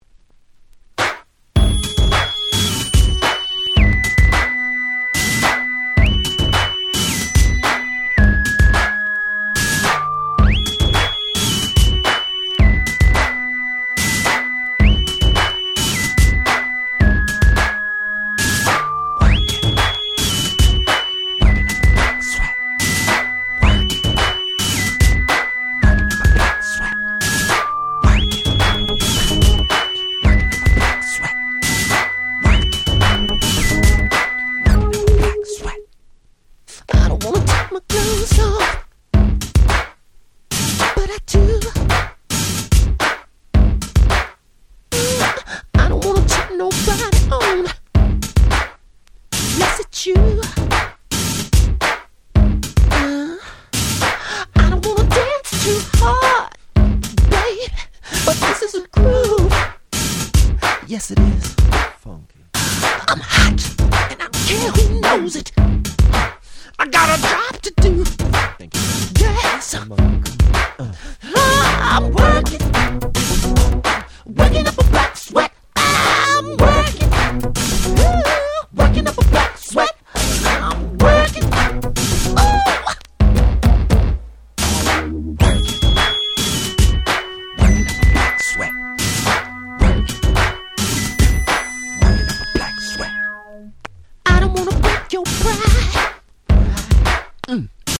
06' Super Nice R&B / Neo Soul !!